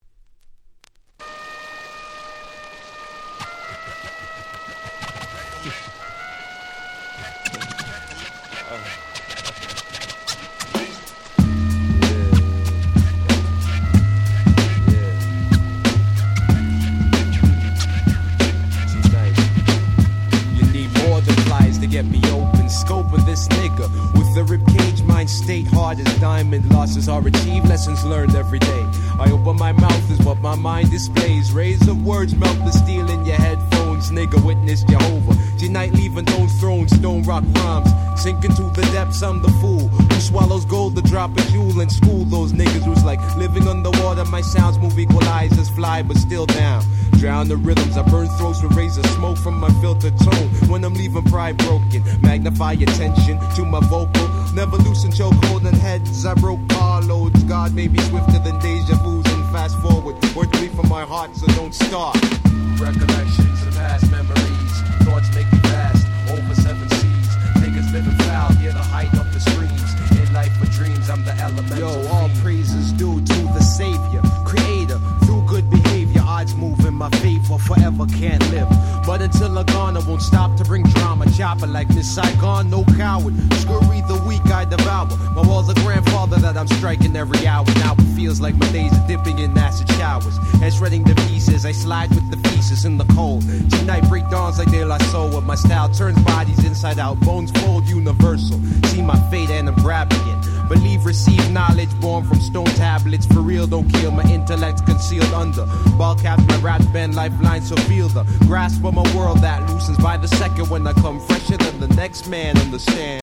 95' Super Nice Canadian Underground Hip Hop !!